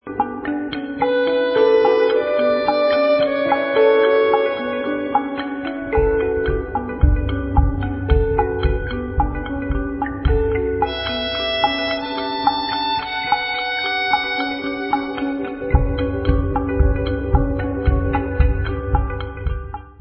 Nahrávalo se ve Zlíně